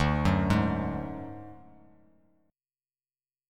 DMb5 chord